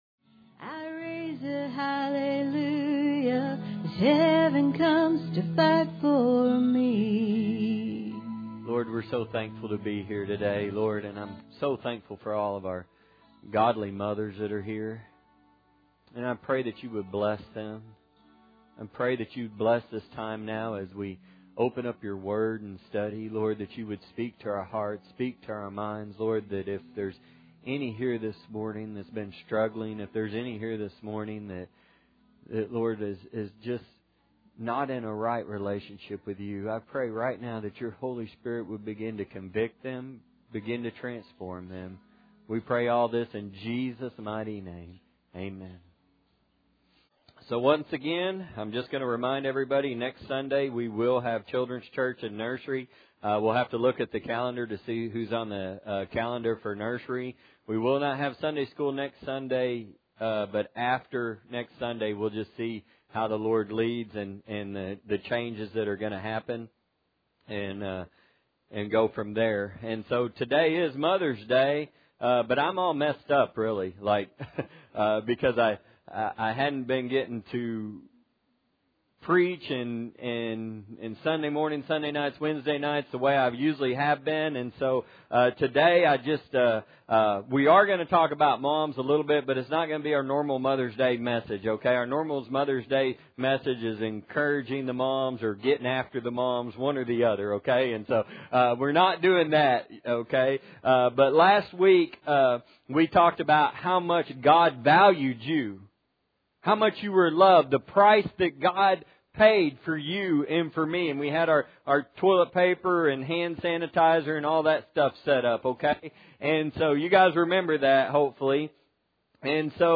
Proverbs 31:26-31 Service Type: Sunday Morning Audio Version Below